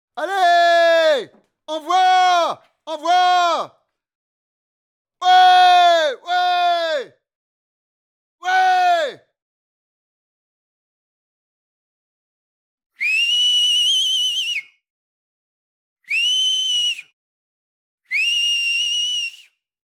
CHARLIE PUBLIC HOMME ENCOURAGE SIFFLEMENT